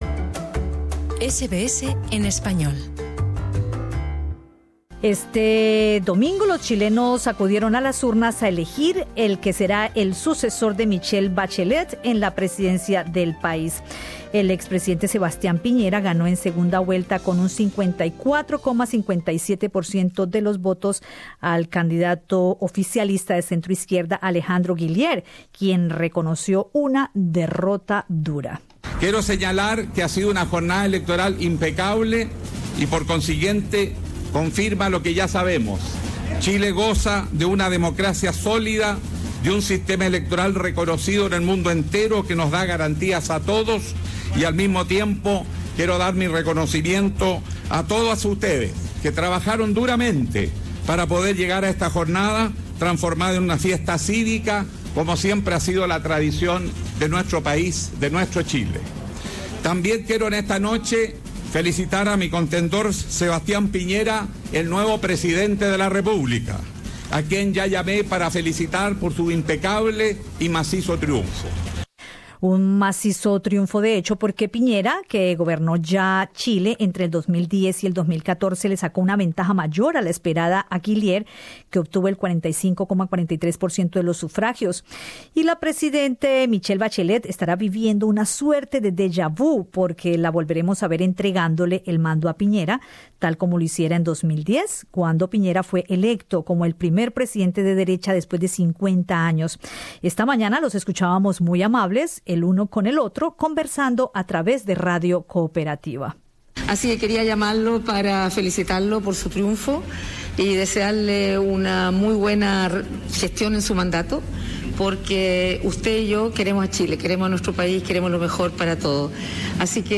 Escucha el podcast con las declaraciones de algunos chilenos que participaron en el proceso electoral, y el balance que hace el cónsul general de Chile en Sydney, Humberto Molina.